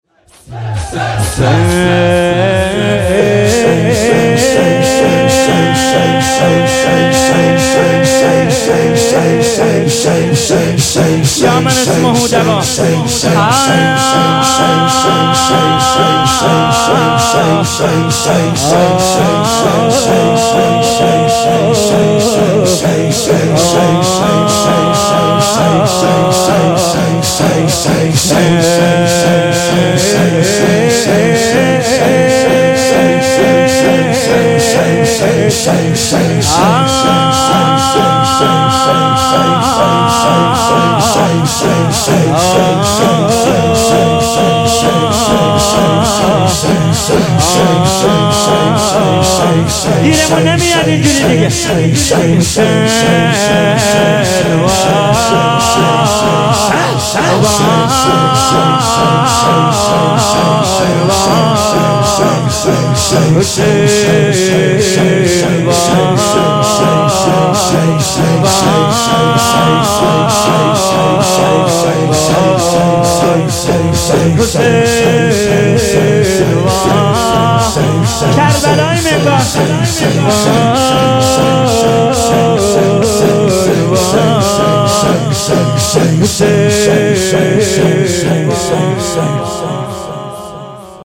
شور - مجلس روضه هفتگی - كربلايی حسين طاهری
هیئت هفتگی 29 آبان